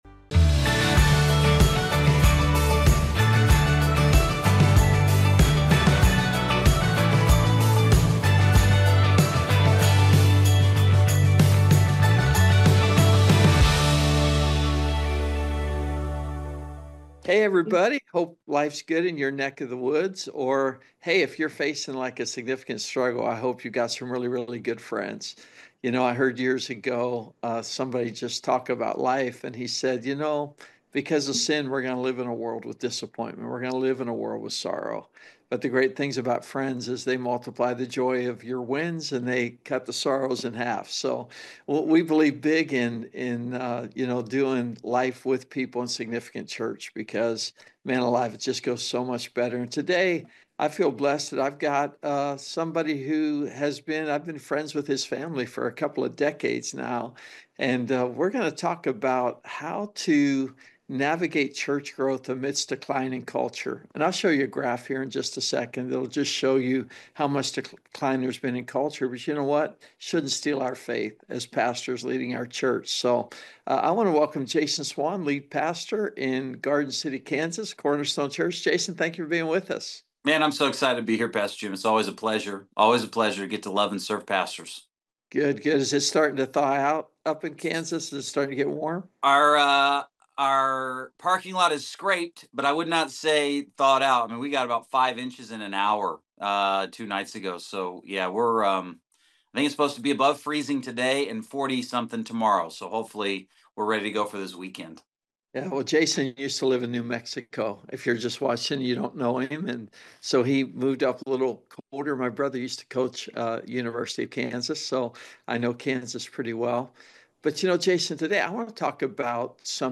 Webinar: Navigating Church Decline and Growth Trends - Significant Church Network